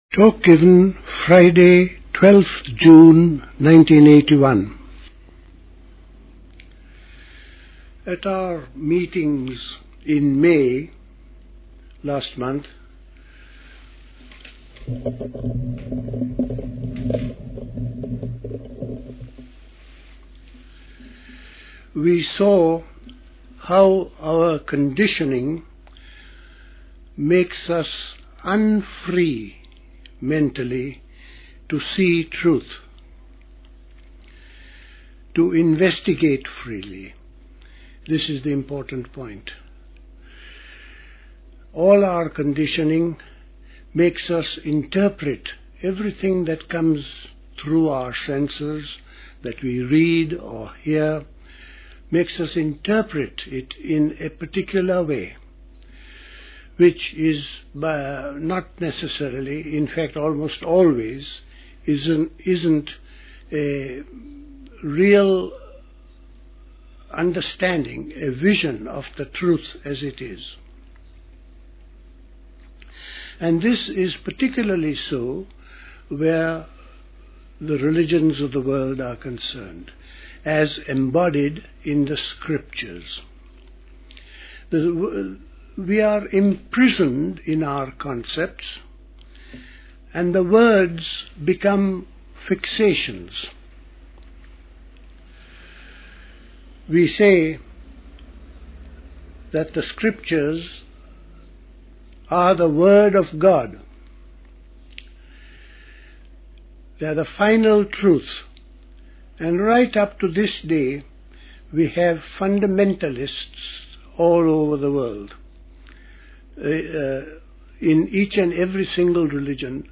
A talk given